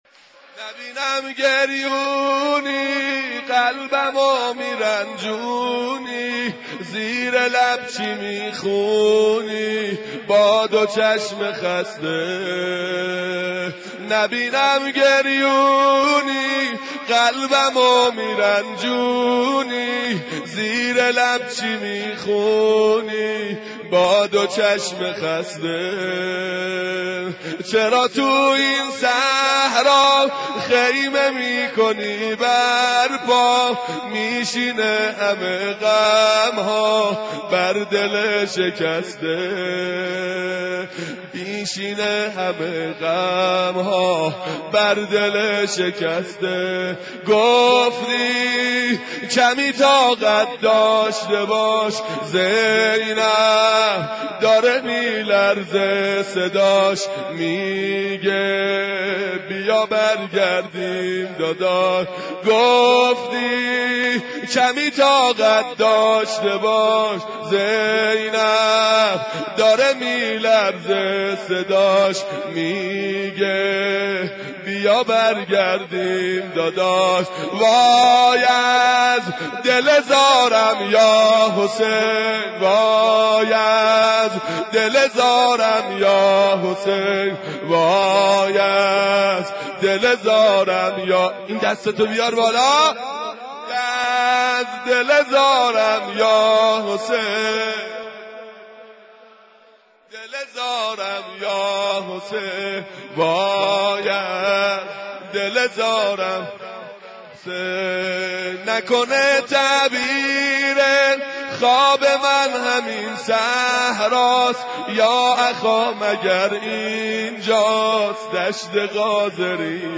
محرم روز دوم ، ورود به کربلا مداح اهل بیت استاد